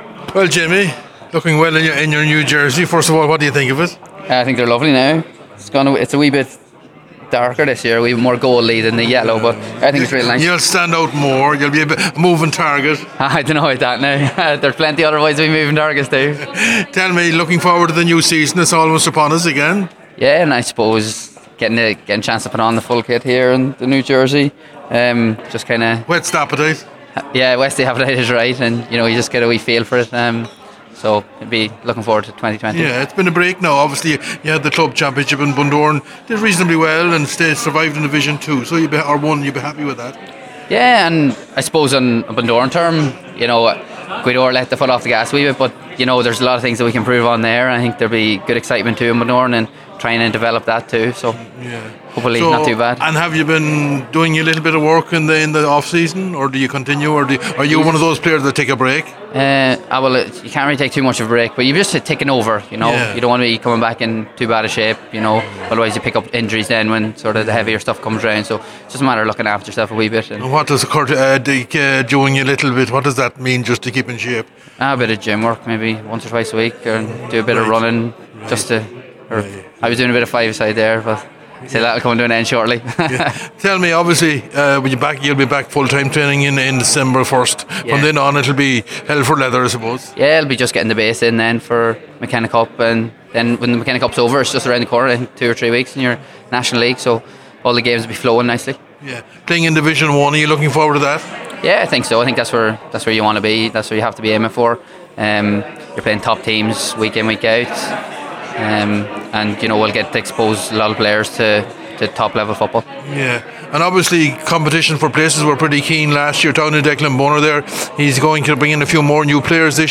At the launch of Donegal’s new jersey ahead of the 2020 season